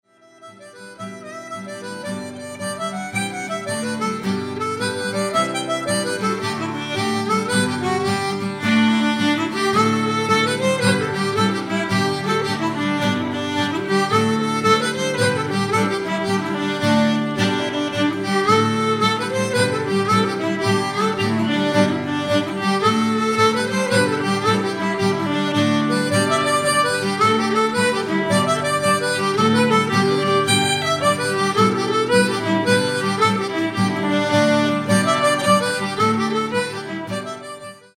jigs
• Diatonic harmonicas
Acoustic guitar, bass guitar, fiddle, mandolin, vocals